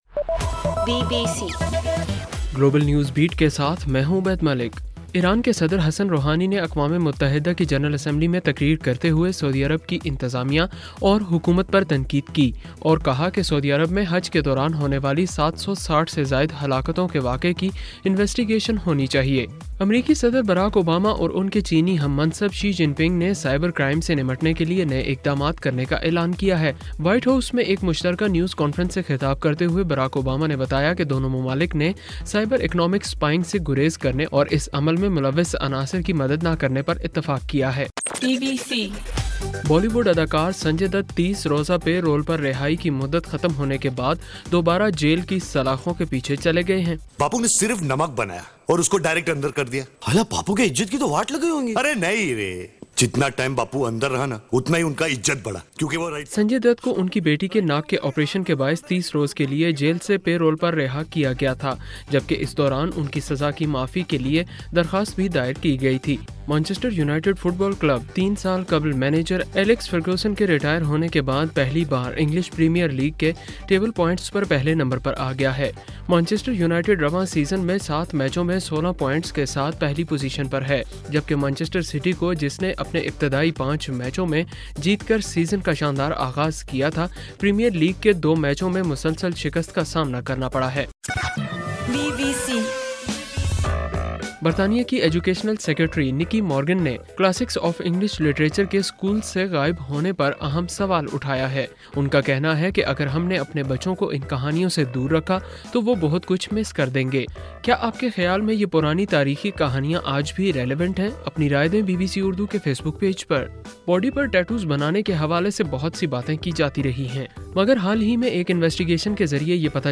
ستمبر 27:صبح 1 بجے کا گلوبل نیوز بیٹ بُلیٹن